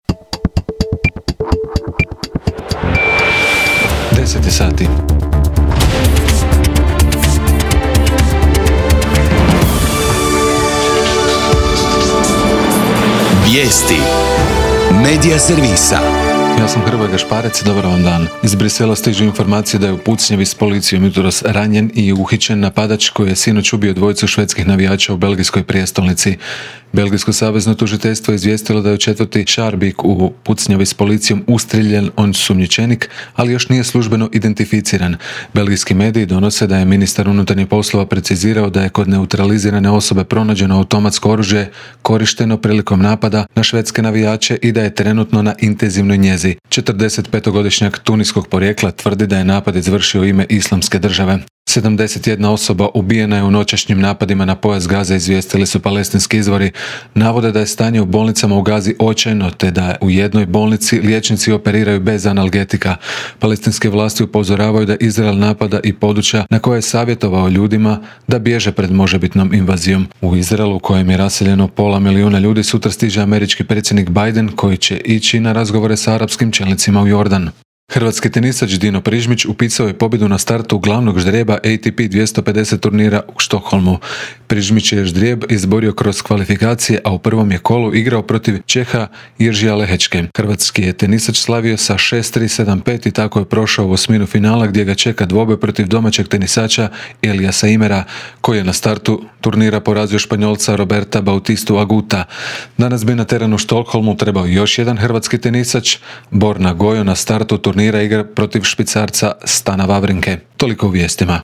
VIJESTI U 10